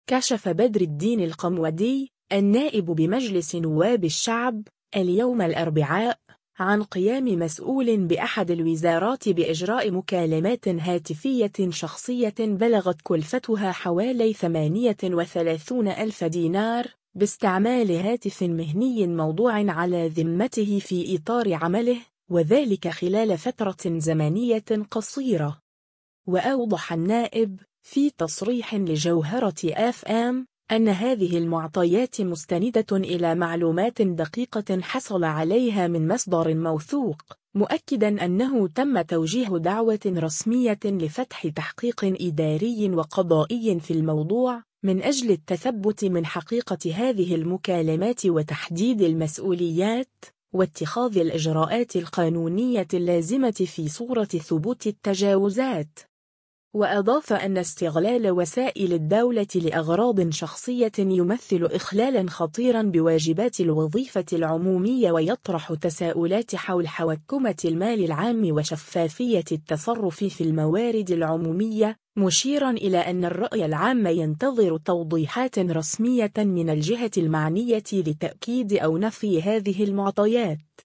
وأوضح النائب، في تصريح لجوهرة آف آم ، أنّ هذه المعطيات مستندة إلى معلومات دقيقة حصل عليها من مصدر موثوق، مؤكّدًا أنّه تمّ توجيه دعوة رسمية لفتح تحقيق إداري وقضائي في الموضوع، من أجل التثبّت من حقيقة هذه المكالمات وتحديد المسؤوليات، واتخاذ الإجراءات القانونية اللازمة في صورة ثبوت التجاوزات.